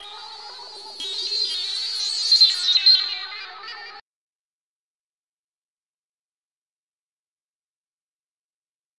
描述：宇宙飞船内可能发生的警报
标签： 外太空 SF 空间船舶 外来
声道立体声